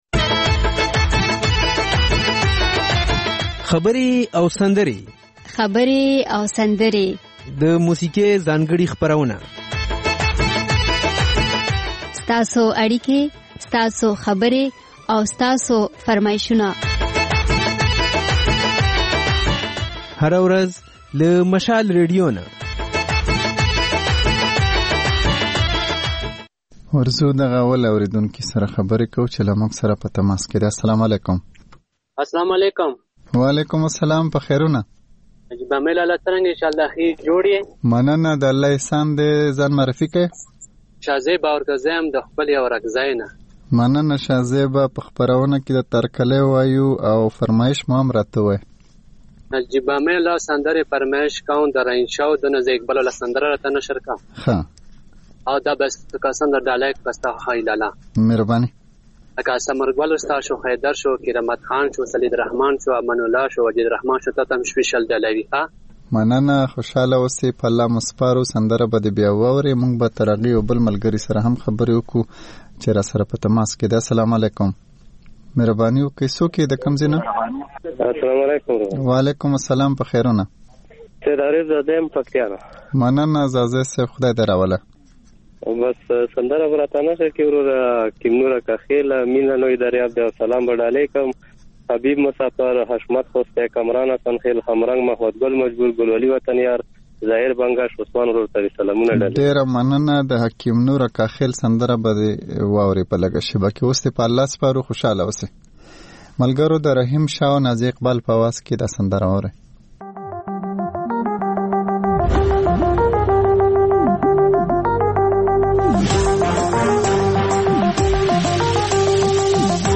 په دې خپرونه کې له اورېدونکو سره خبرې کېږي، د هغوی پیغامونه خپرېږي او د هغوی د سندرو فرمایشونه پوره کېږي. دا یو ساعته خپرونه د پېښور پر وخت سهار پر څلور او د کابل پر درې نیمو بجو تکرار خپرېږي.